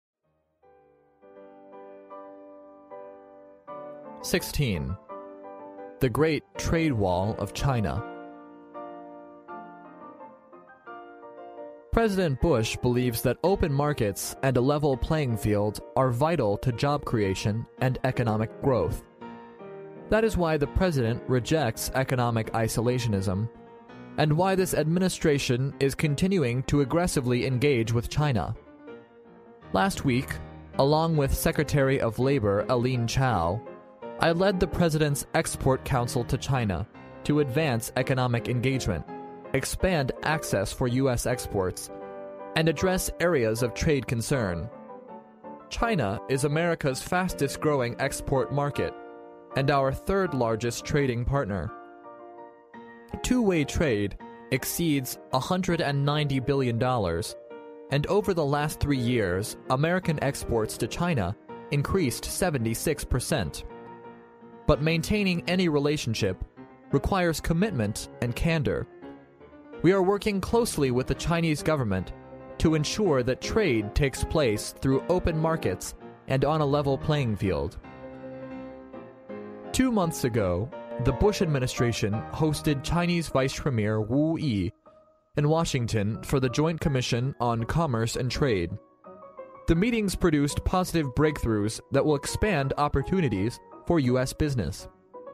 历史英雄名人演讲 第85期:中国的(贸易)长城(1) 听力文件下载—在线英语听力室